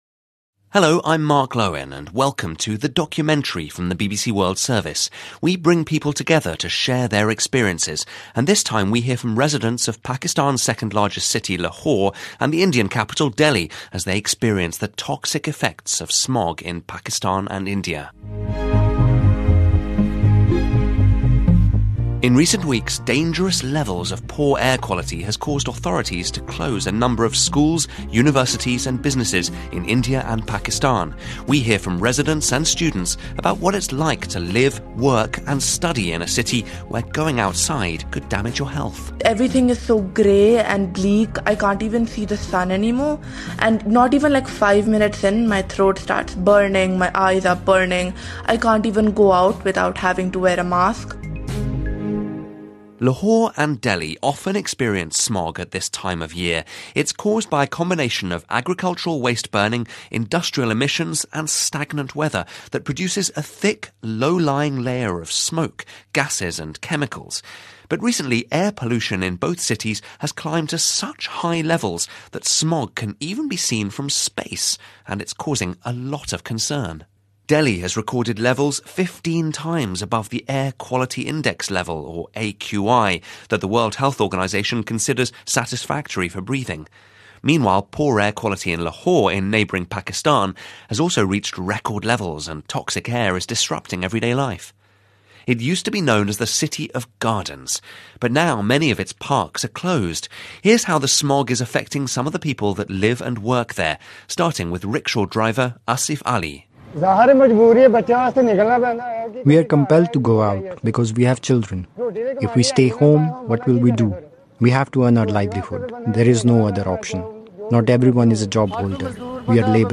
She finds out where the hypnotising, jazzy, soulful sound emerged from, what it means culturally, and how it became South Africa's signature music genre of the 2020s.